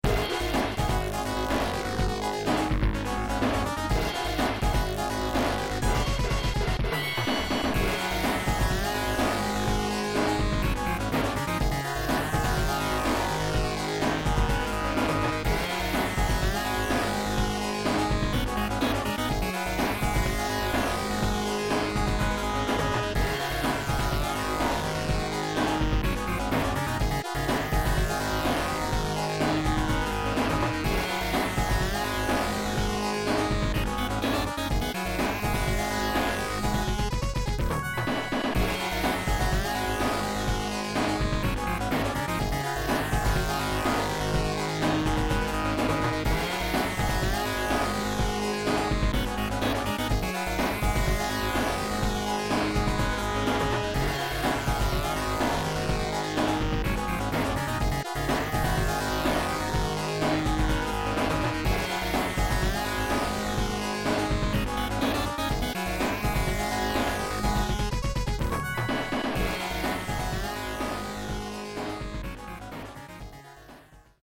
All of this music is from the arcade version of the game.